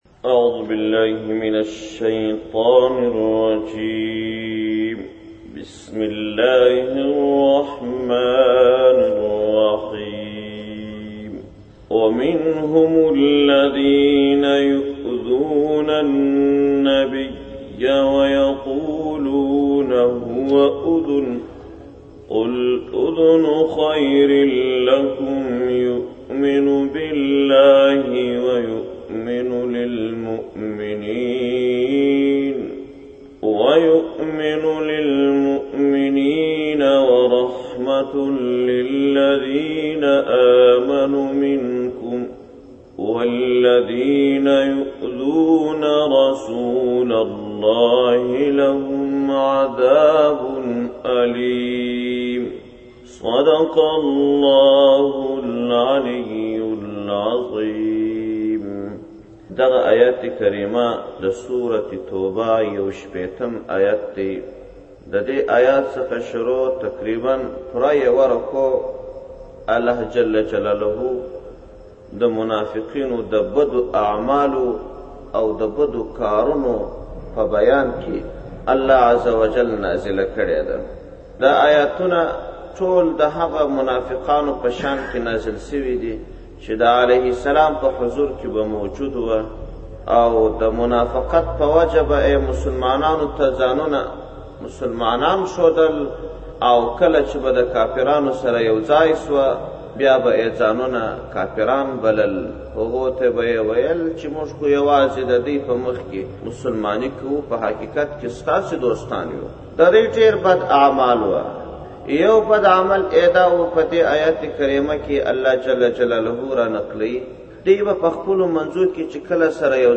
مارچ 27, 2017 تفسیرشریف, ږغیز تفسیر شریف 711 لیدنی